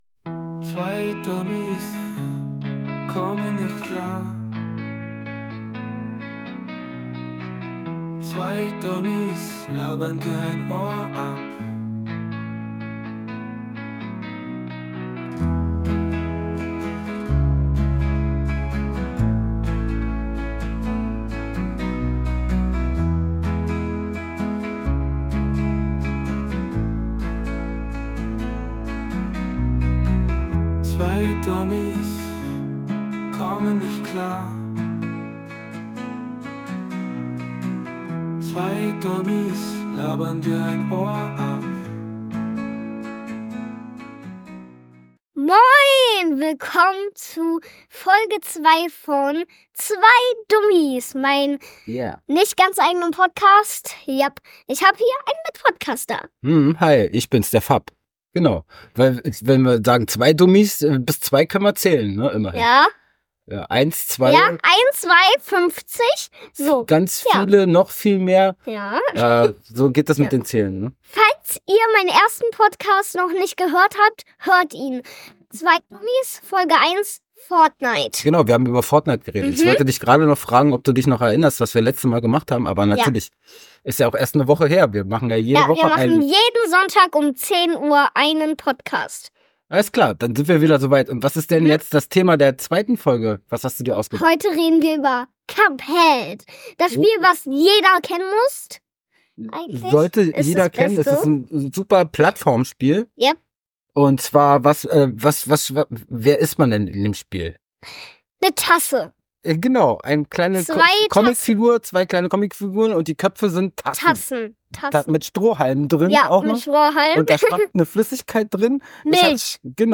Die Moderatoren beschreiben ausführlich die Handlung des Spiels, in der die Tassenkopf-Brüder Cuphead und Mugman versuchen, ihre Seelen zu retten, indem sie Bosse besiegen. es gibt verschiedene Level, Gegner, Gameplay-Elemente und das Sammeln von Münzen für Waffen.